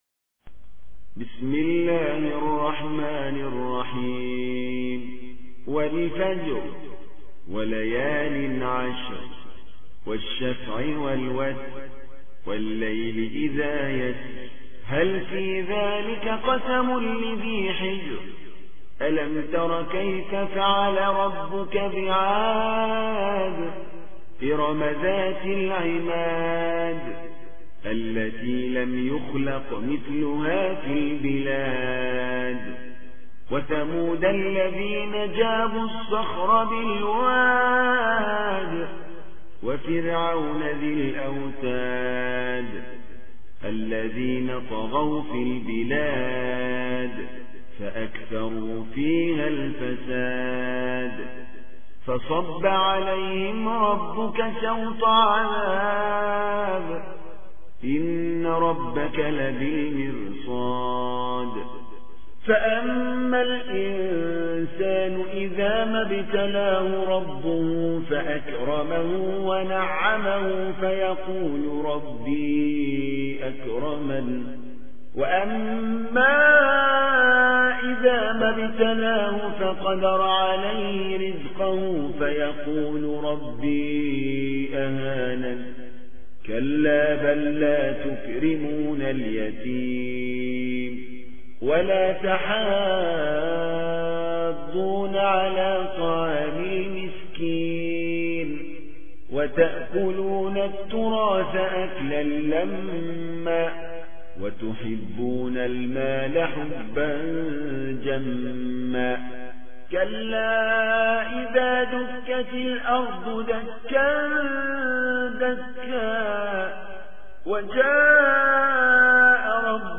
برچسب ها: سوره فجر ، تلاوت ترتیل ، قاری مصری ، ماه محرم